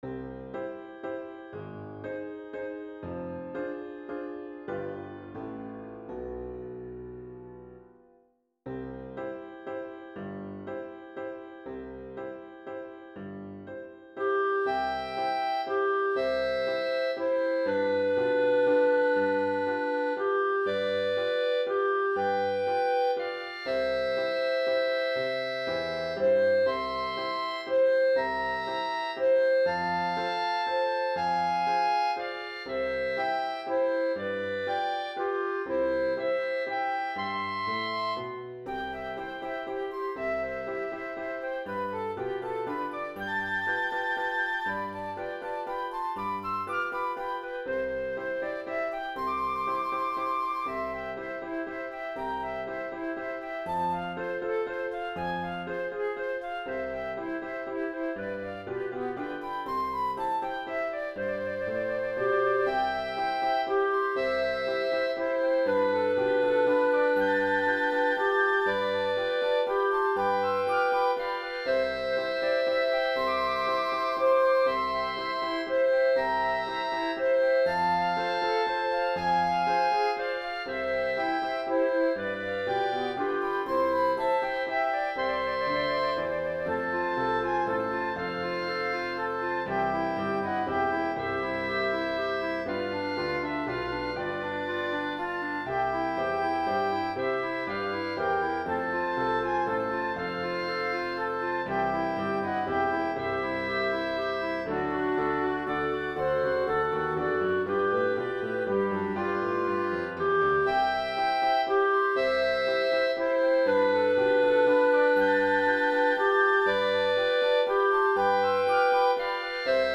This is a traditional style waltz I made up for two flutes, two clarinets and piano. I used the Finale software for the audio output.